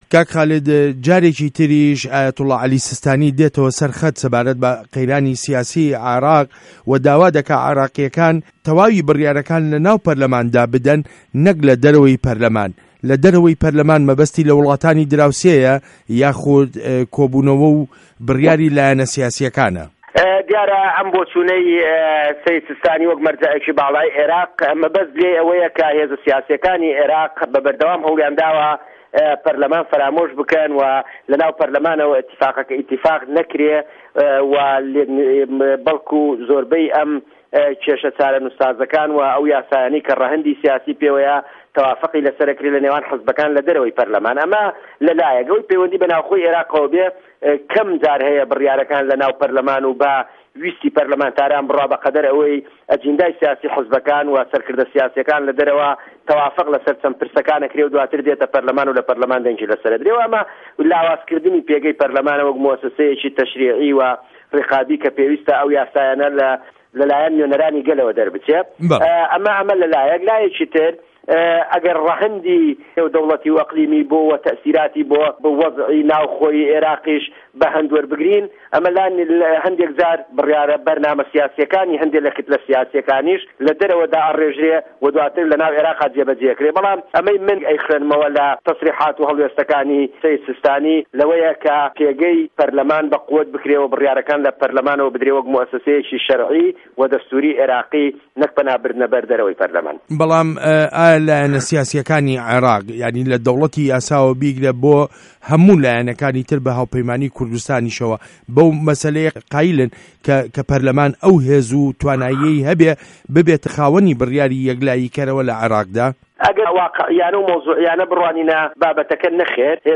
وتووێژ له‌گه‌ڵ خالید شوانی